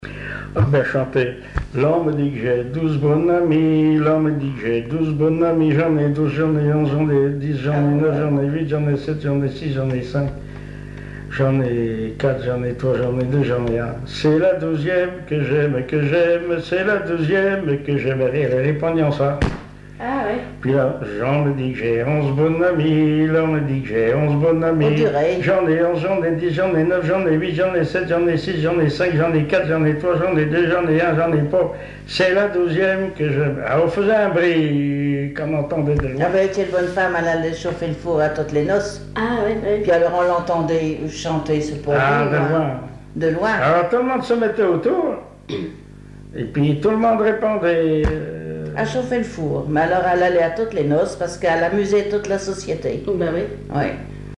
Mémoires et Patrimoines vivants - RaddO est une base de données d'archives iconographiques et sonores.
Genre énumérative
Témoignages et chansons traditionnelles et populaires
Pièce musicale inédite